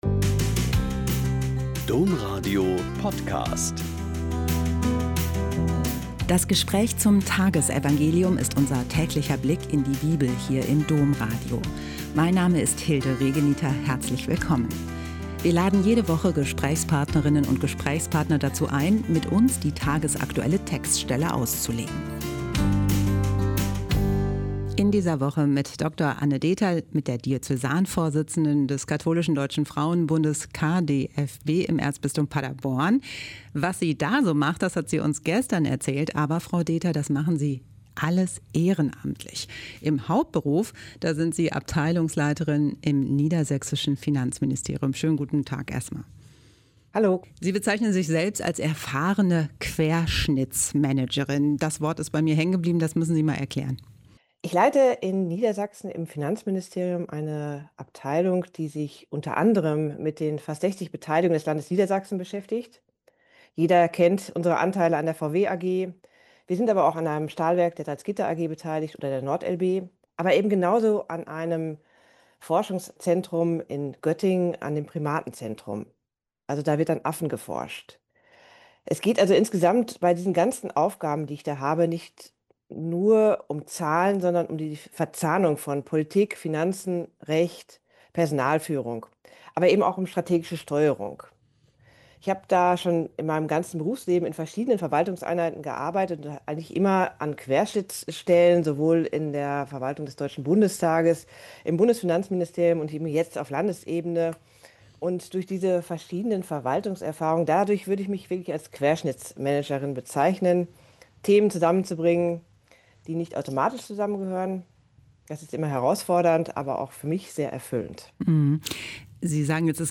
Mt 11,20-24 - Gespräch